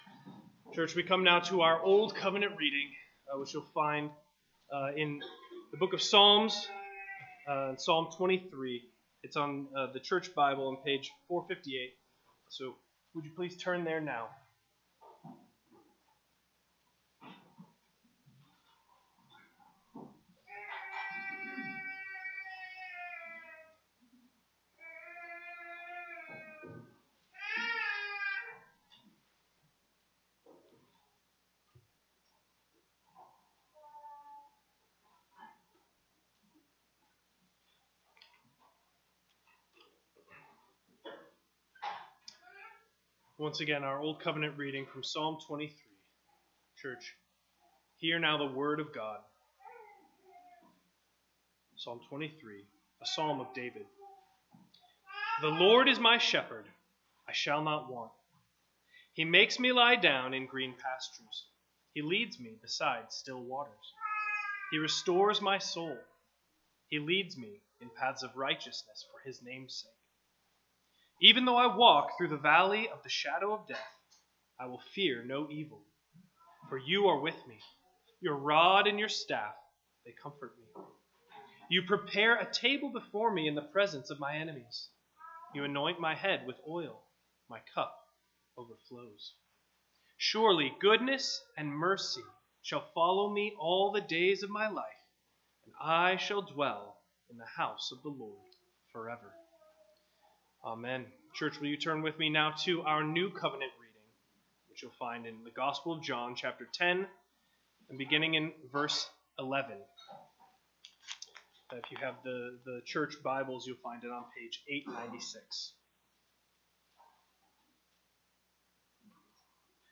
A Sermon from John 10:11–21
Service Type: Sunday Morning